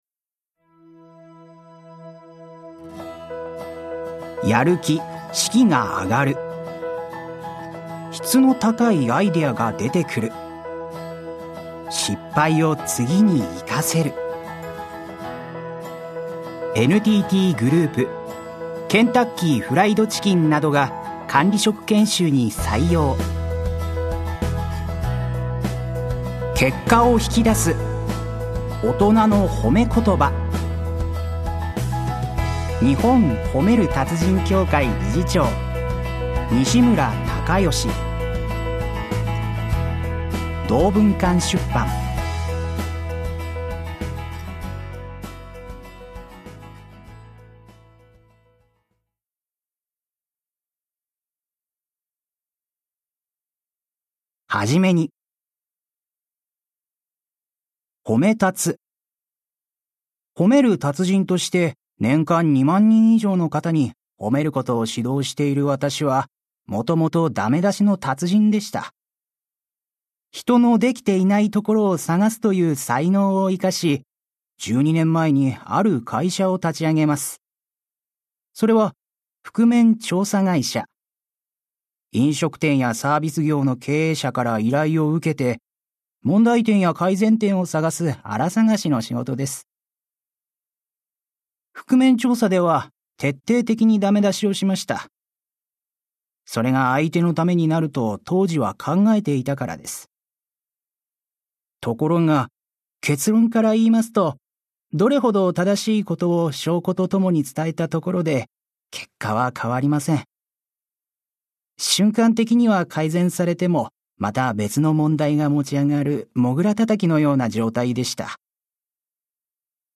[オーディオブック] 結果を引き出す 大人のほめ言葉